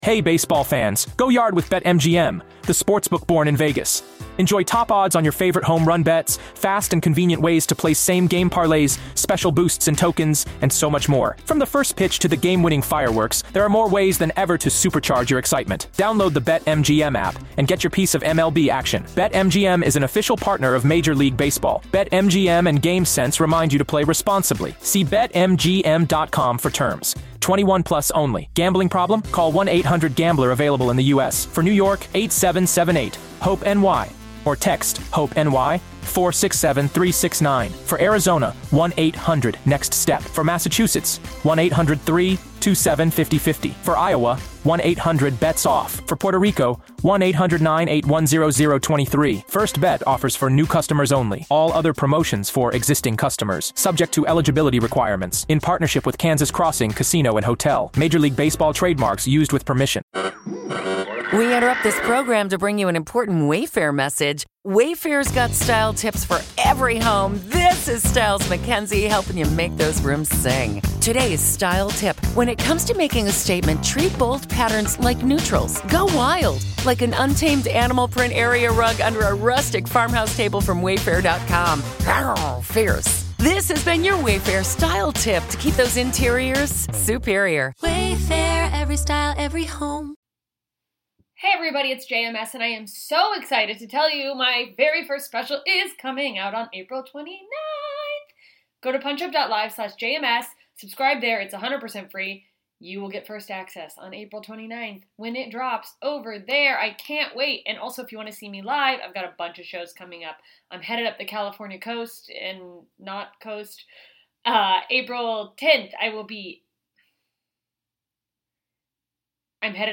Genres: Comedy, Comedy Interviews, Stand-Up